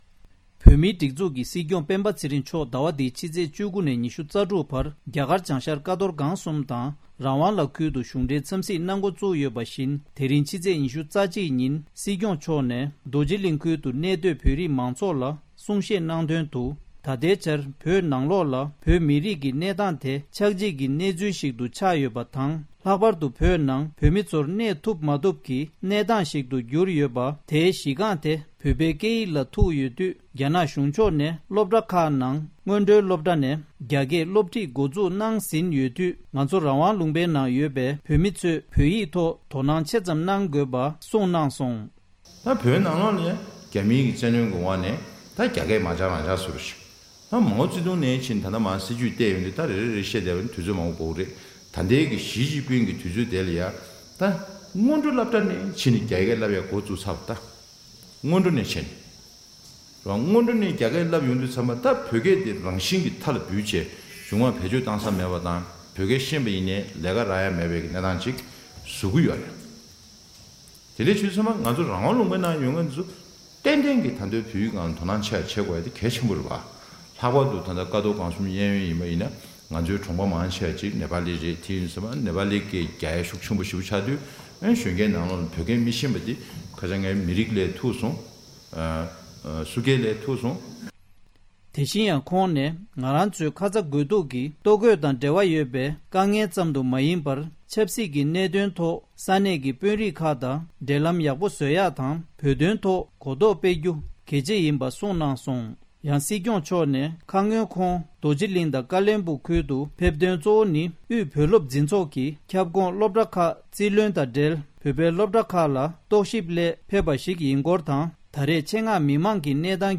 གསར་འགོད་པ།